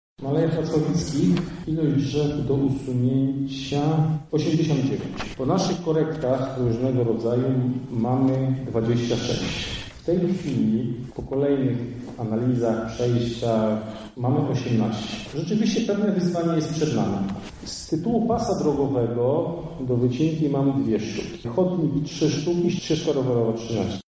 Kwestia ochrony drzew jest elementem jak najbardziej istotnym– mówi zastępca prezydenta Miasta Lublin Artur Szymczyk